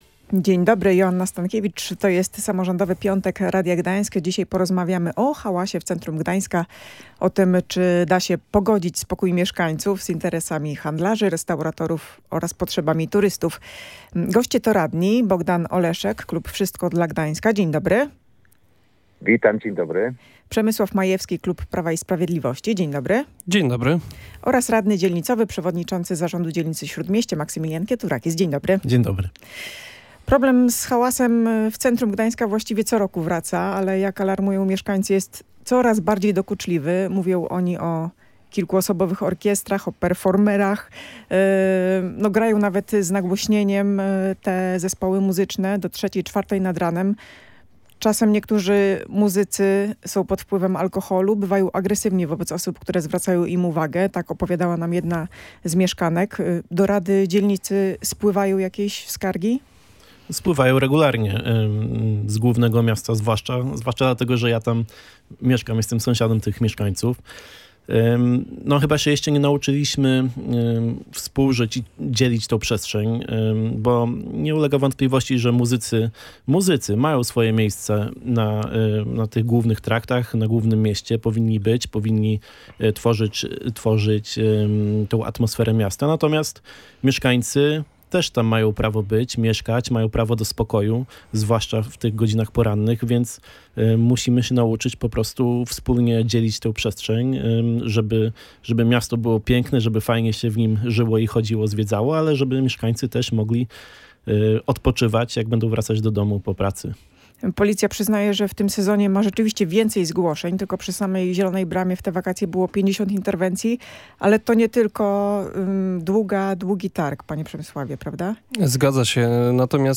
O tym, jak rozwiązać ten problem, dyskutowali Przemysław Majewski, radny klubu Prawo i Sprawiedliwość, Bogdan Oleszek, radny klubu Wszystko dla Gdańska oraz Maximilian Kieturakis, przewodniczący Zarządu Dzielnicy Śródmieście.